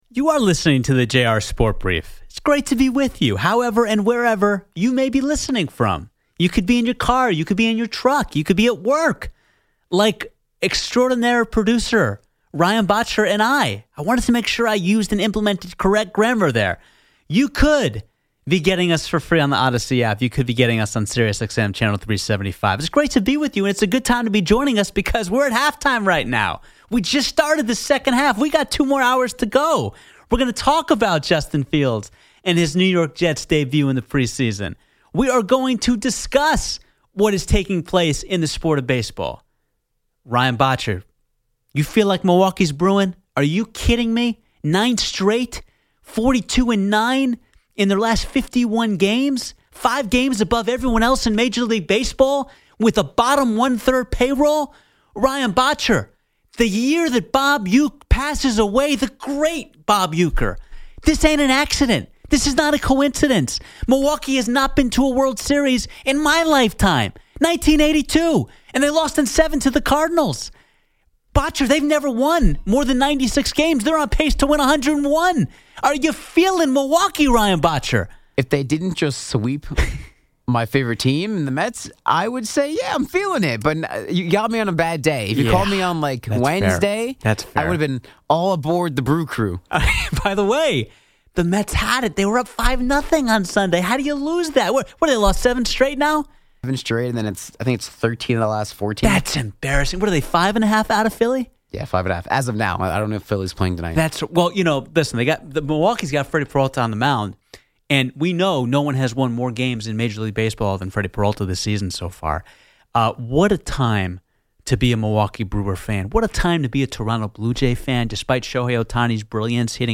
Justin Fields has a good first game with the Jets. | Your calls!